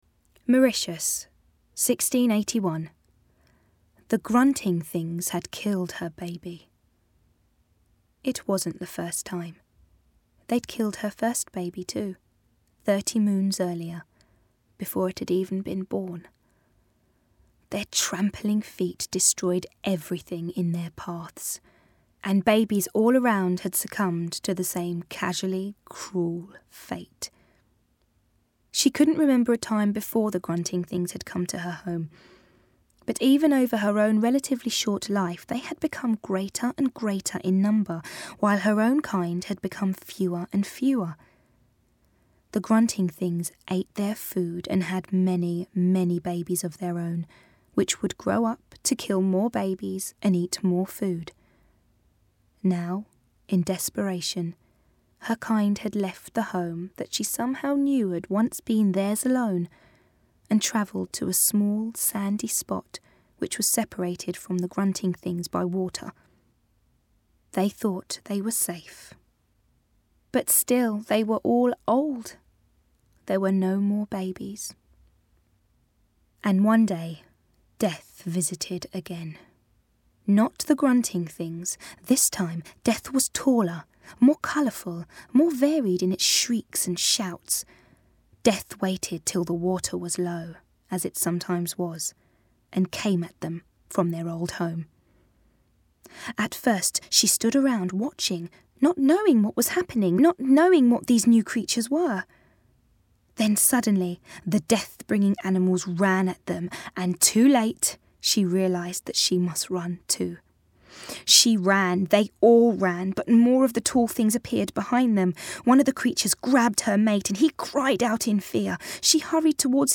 Preview of the new audiobooks:
DOCTOR WHO - THE LAST DODO - BBC AUDIO - Read by Freema Agyeman